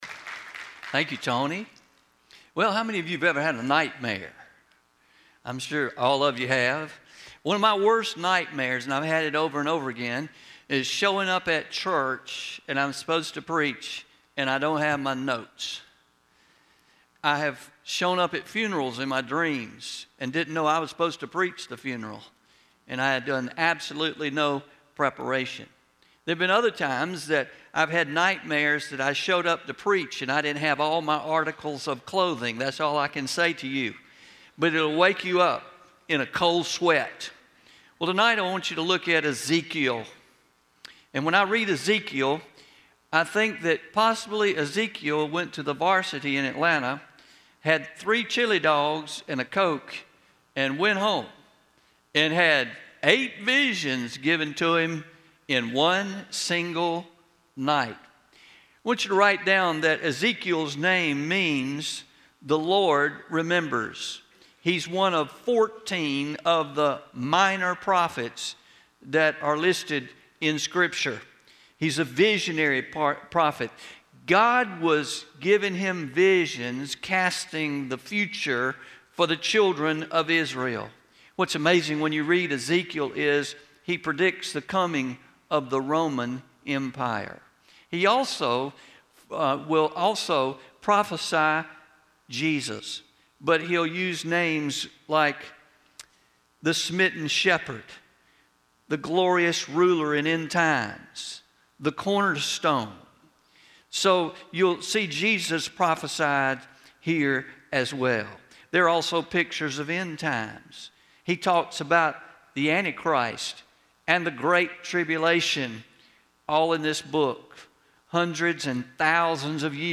11-02-22pm Sermon – You Dreamed What